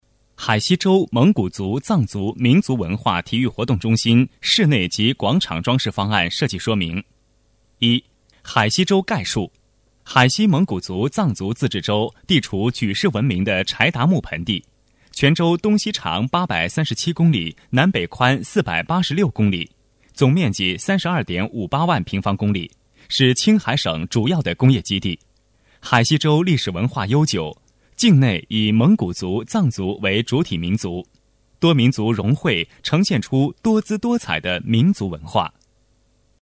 工程解说男305号（年轻干
轻松自然 规划总结配音
年轻磁性男音，干练。